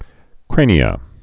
(krānē-ə)